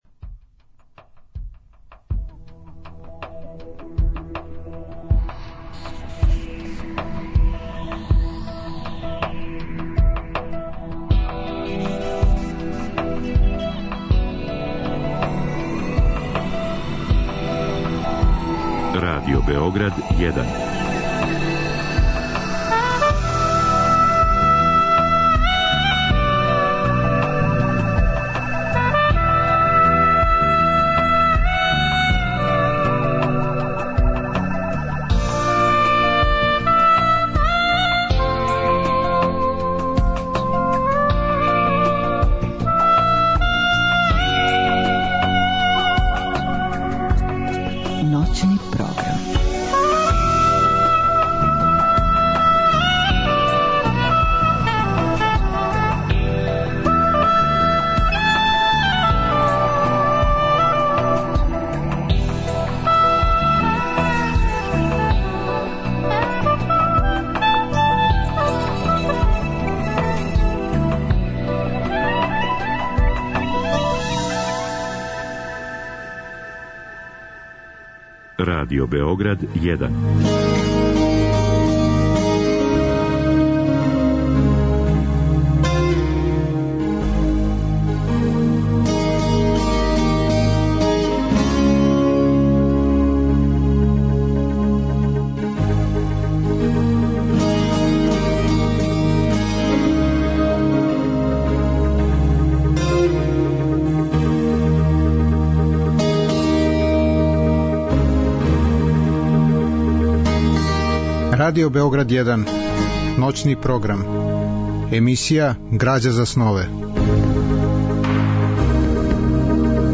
Radio Beograd 1
Razgovor i dobra muzika trebalo bi da kroz ovu emisiju i sami postanu građa za snove.
U drugom delu emisije, od dva do četiri časa ujutro, slušaćemo odabrane delove iz knjige Znakovi pored puta Ive Andrića koje interpretira dramski umetnik Milan Caci Mihailović.